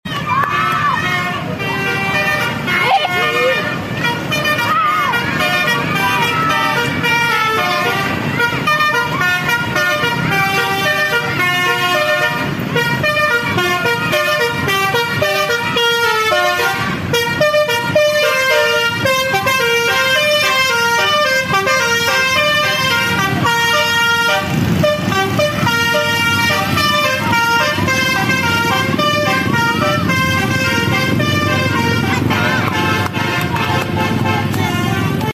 Basuri DAV Best Premium RIGEL Sound Effects Free Download